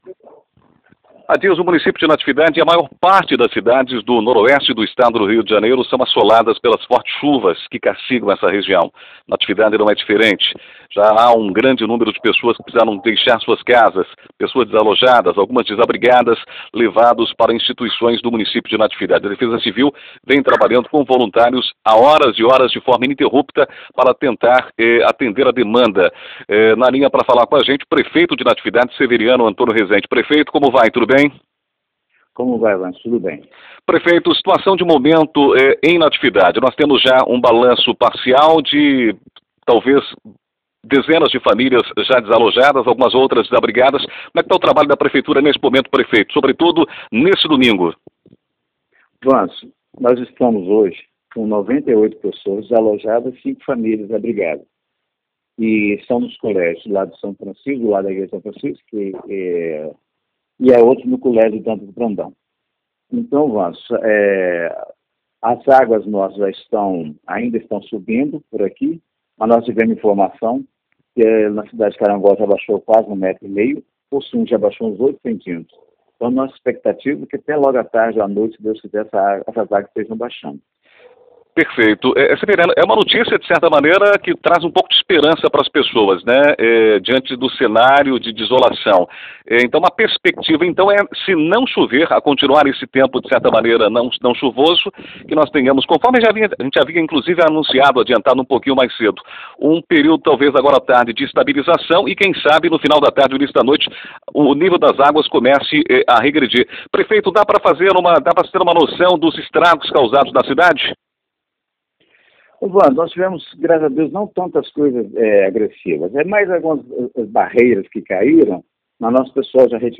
Prefeito de Natividade fala à Rádio Natividade e diz estar com equipes já preparadas para limpar a cidade – OUÇA
26 janeiro, 2020 ENTREVISTAS, NATIVIDADE AGORA
Entrevista-Severiano.mp3